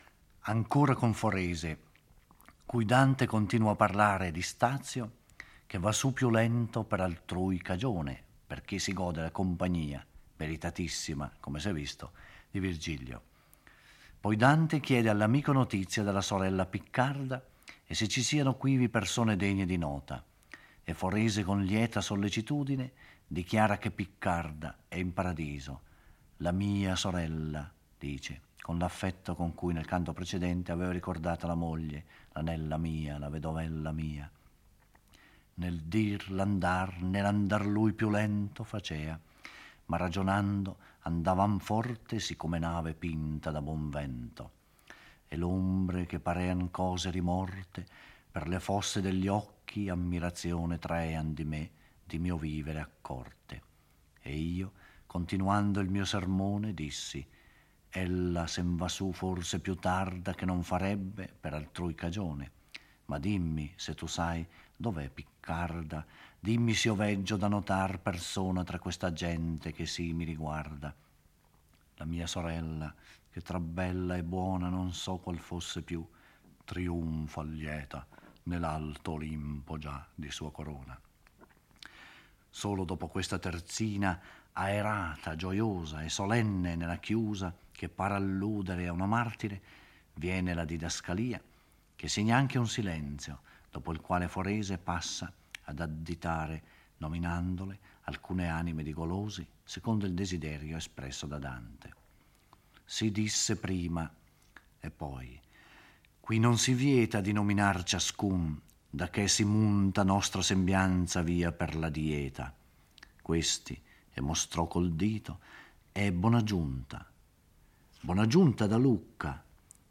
Giorgio Orelli legge e commenta il XXIV canto del Purgatorio. È camminando e discorrendo con il suo amico Forese che Dante apprende che la sorella è giunta in Paradiso.